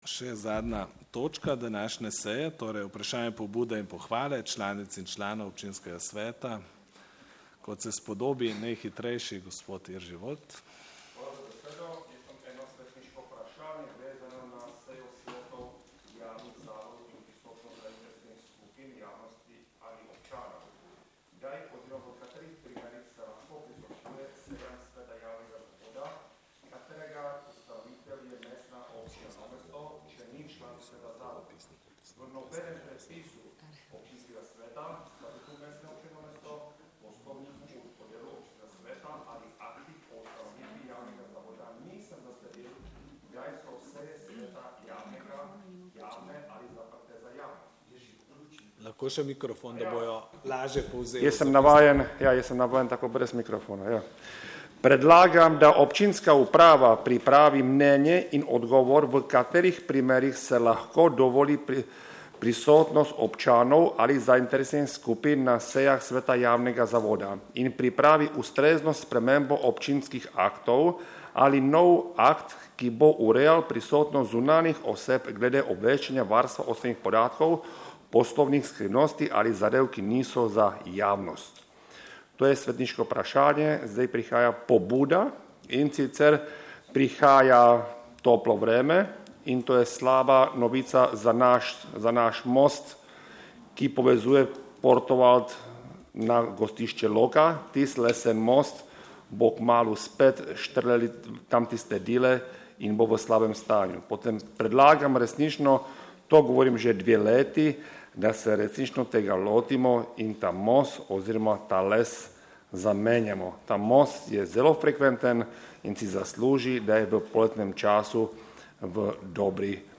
21. seja Občinskega sveta Mestne občine Novo mesto